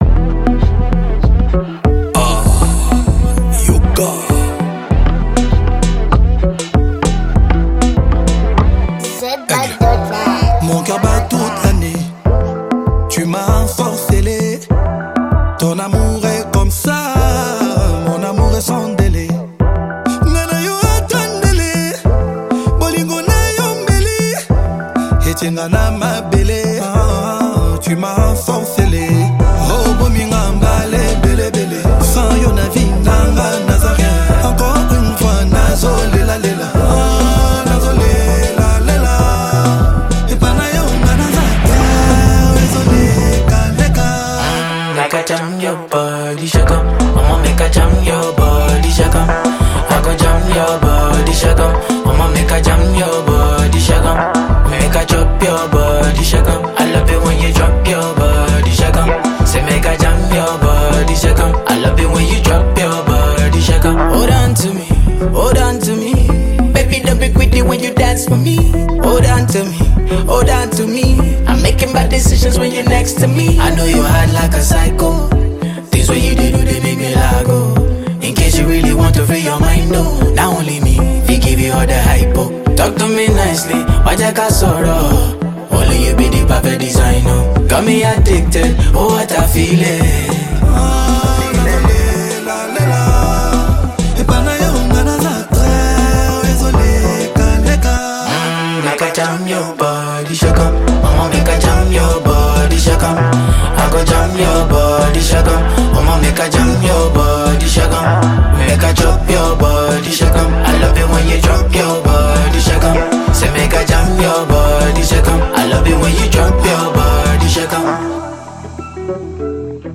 you'll notice the beat is catchy and upbeat.